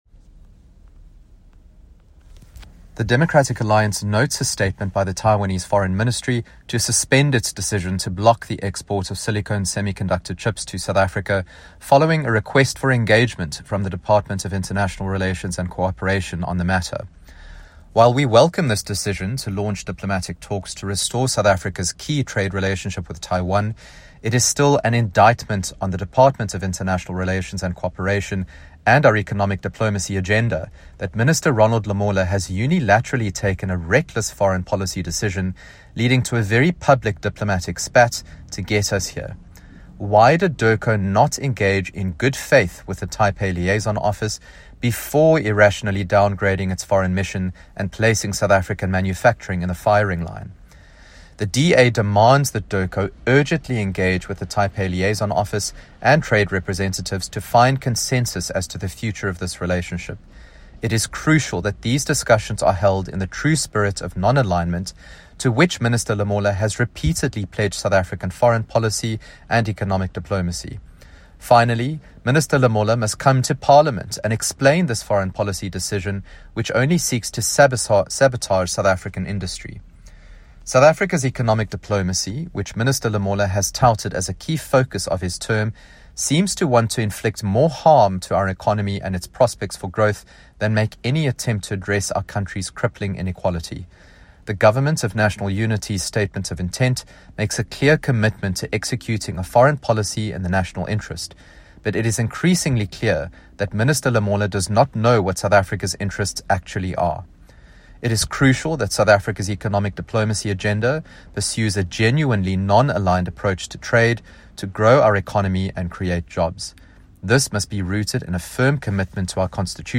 soundbite by Ryan Smith MP.